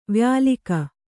♪ vyālika